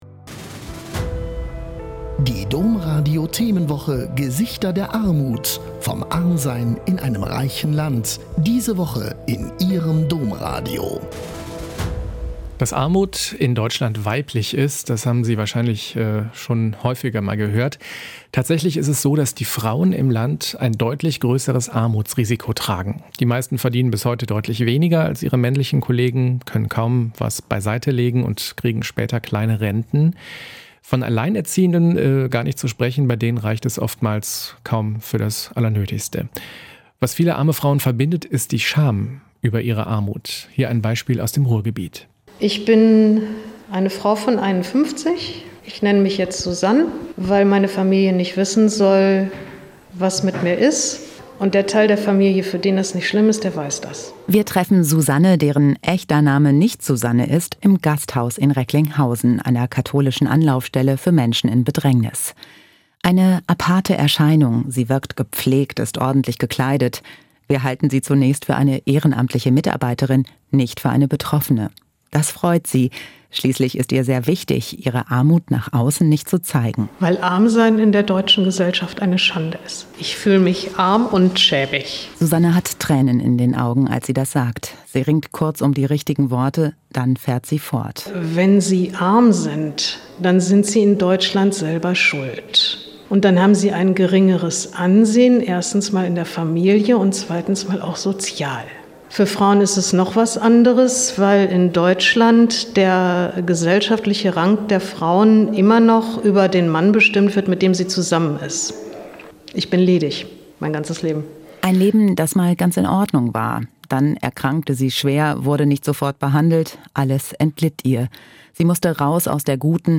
Interviewmitschnitte Domradio Köln (Nov.2017)